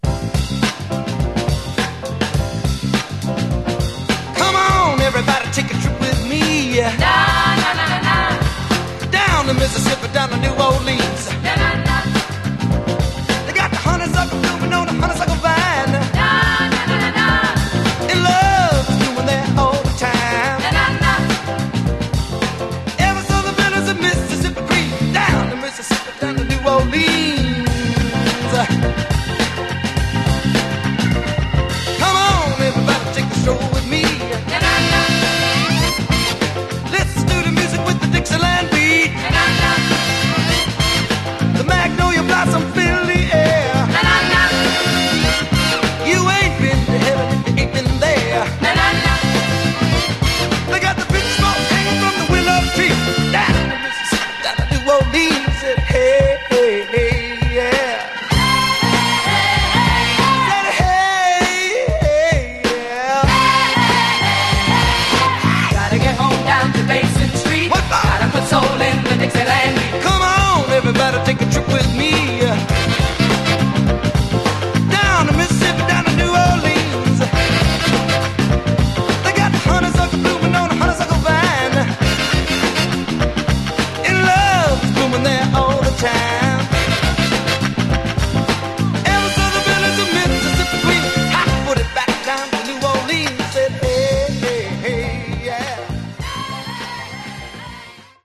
Category: Funky Soul